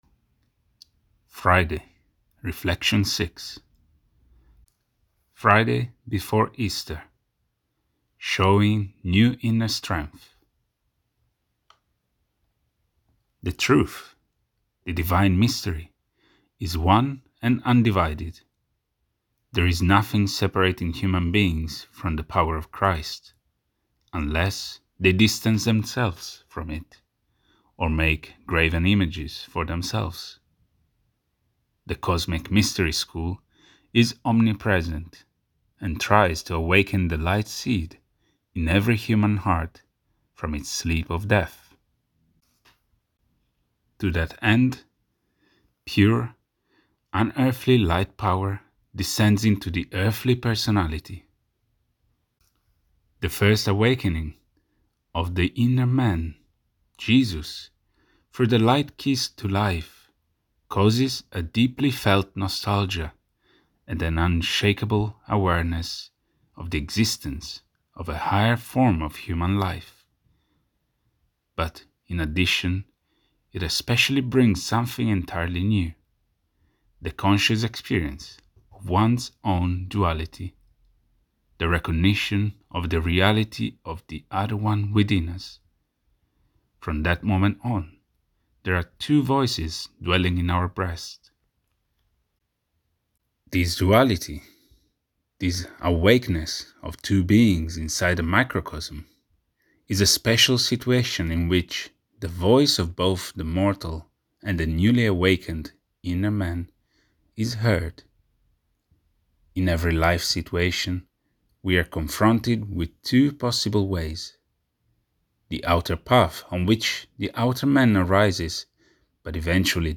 Narrative